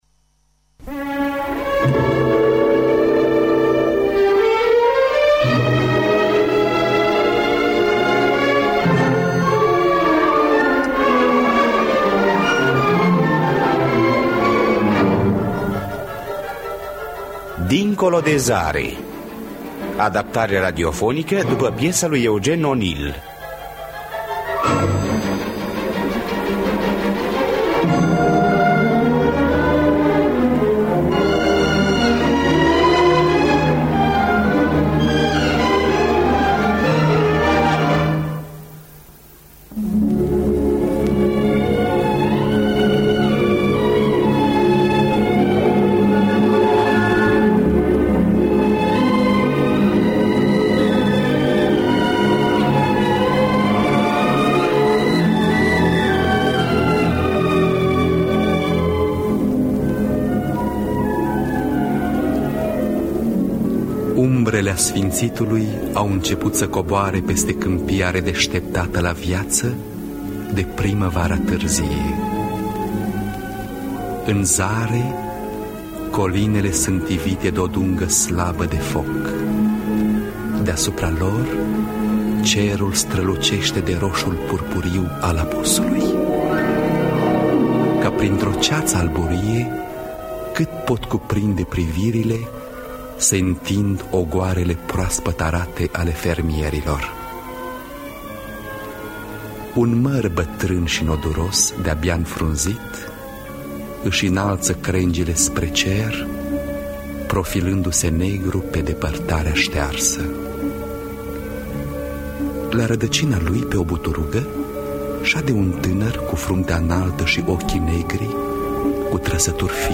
„Dincolo de zare” de Eugene O’Neill – Teatru Radiofonic Online